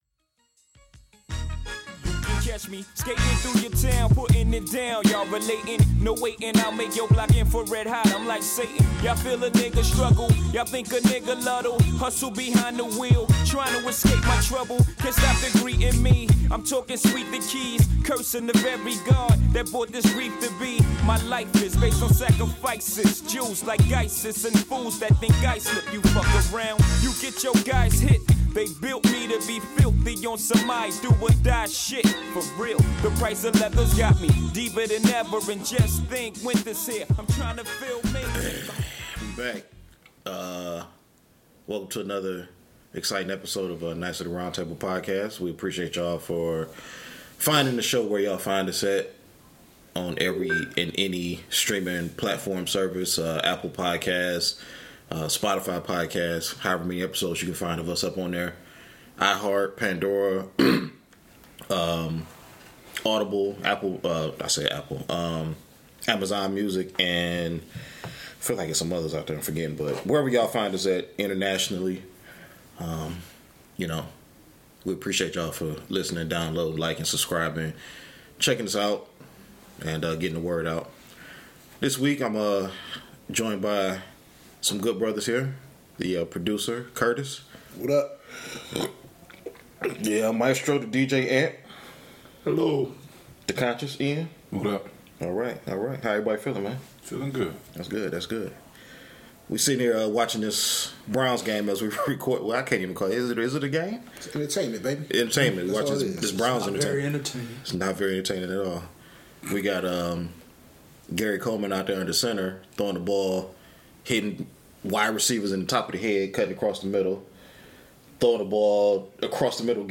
No topic is off limits and no take is too edgy for them to tackle. Unfiltered and uncensored takes is what you are going to get from these men so brace yourself!